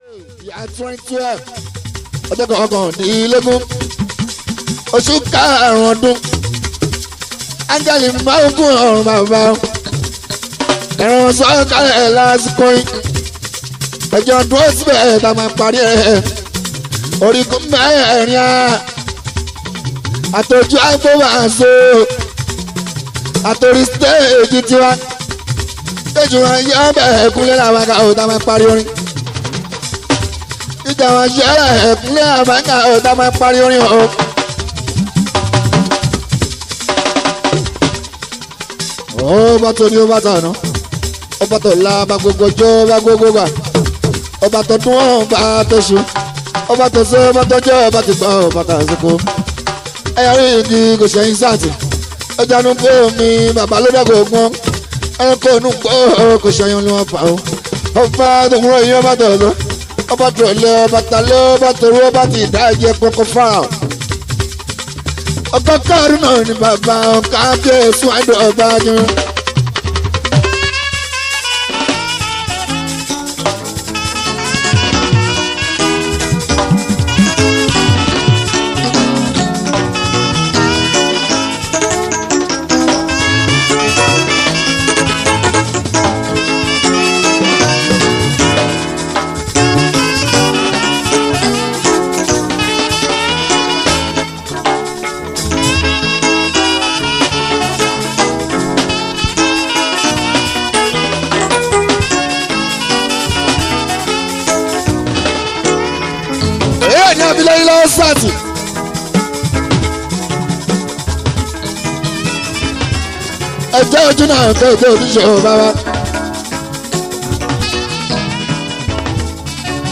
Yoruba Fuji song